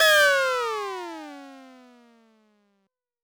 Waka TRAP TRANSITIONZ (4).wav